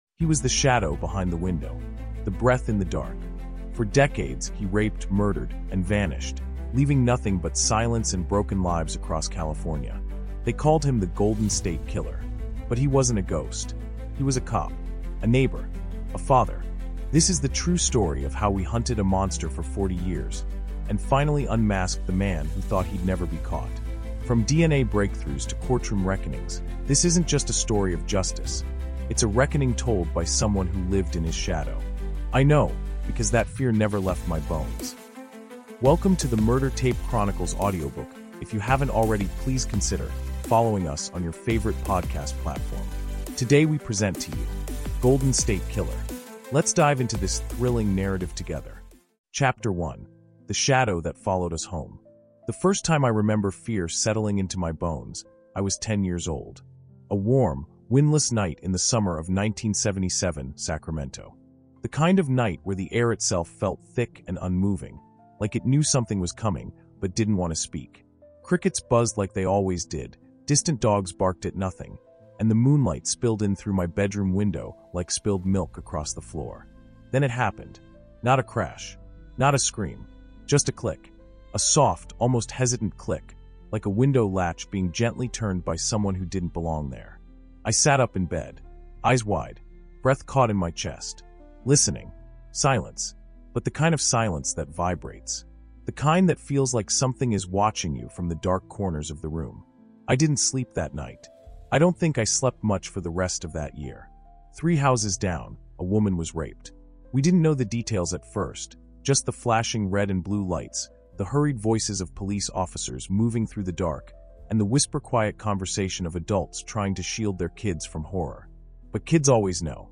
Golden State Killer | Audiobook
Golden State Killer Unmasked is a chilling, five-part true crime audiobook told in raw, first-person narration by a man who grew up haunted by a predator the world believed had vanished. From the fear-soaked suburbs of 1970s California to the DNA-driven manhunt that shook the nation in 2018, this story unearths the decades-long nightmare of one of America’s most terrifying serial offenders—revealing how justice finally caught up to a man who lived among us, hiding in plain sight.